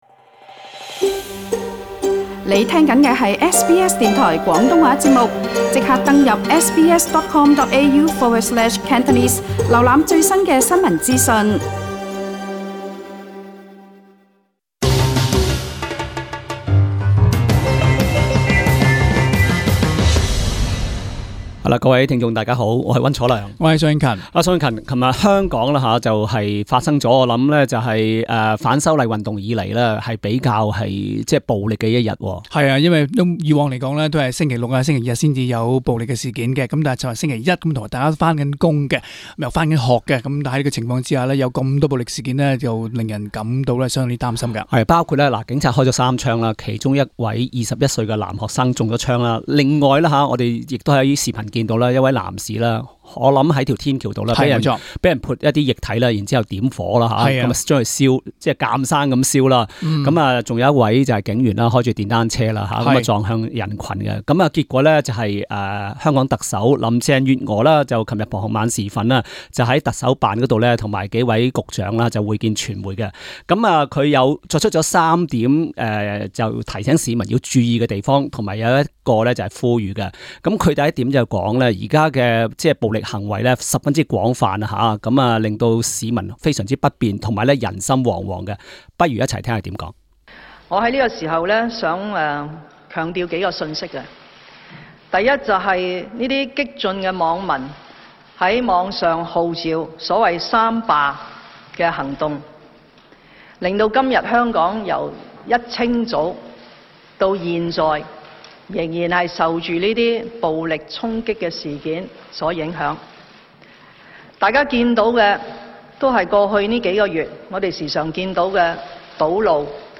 Hong Kong Chief Executive Carrie Lam, center, speaks during a press conference in Hong Kong, Monday, Nov. 11, 2019.